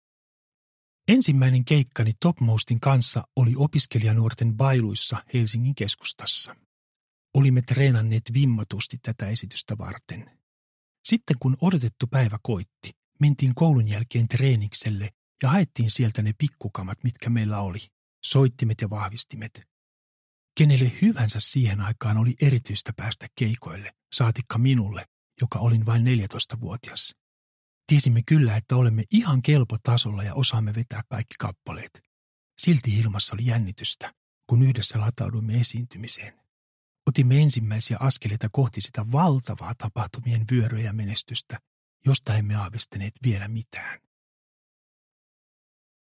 Kisu - Onnestain on puolet sinun – Ljudbok – Laddas ner